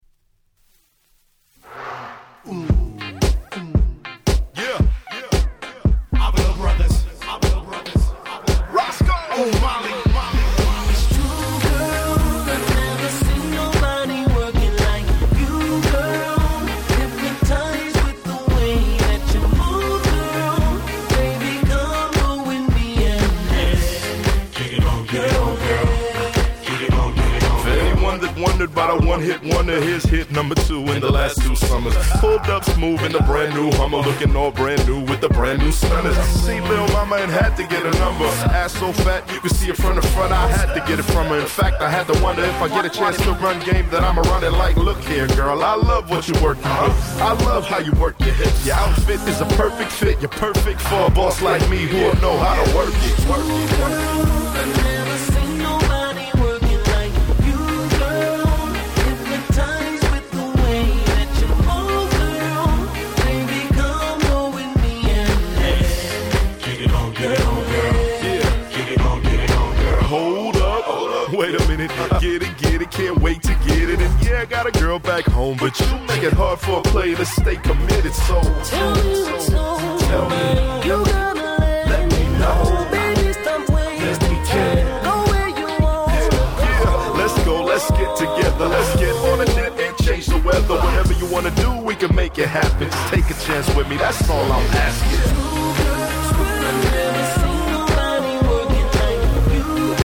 08' Nice Hip Hop/R&B !!